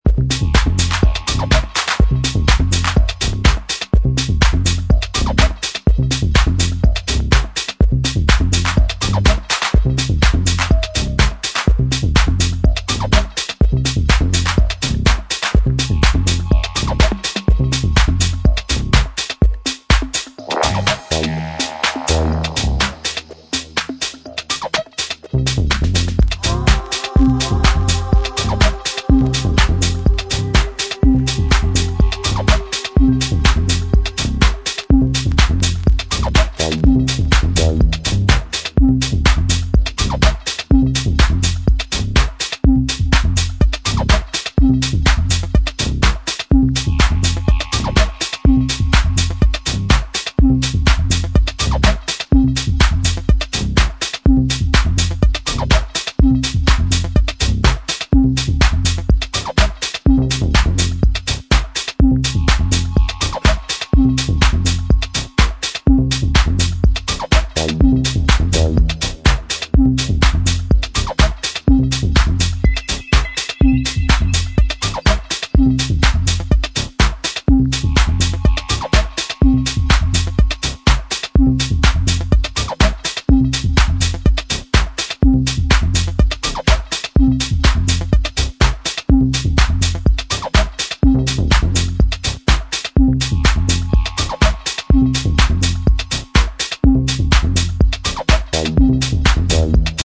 Their minimal-inclined track